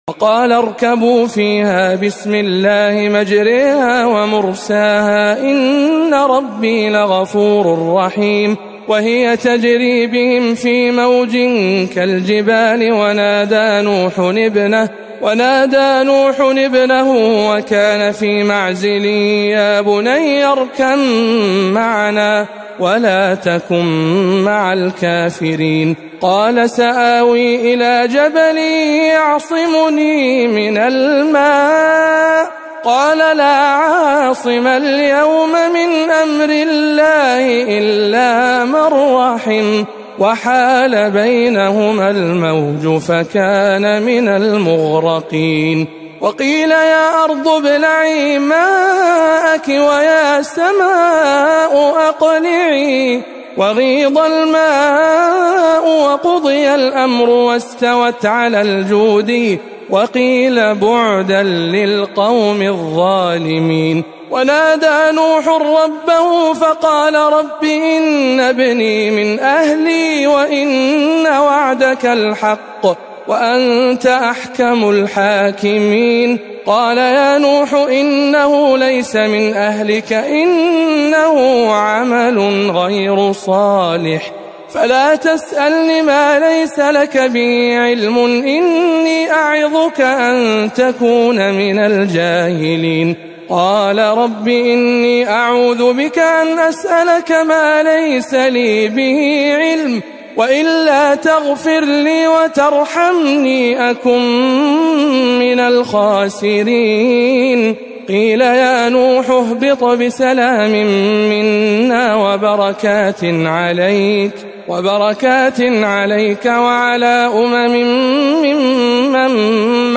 تلاوة مميزة من سورة هود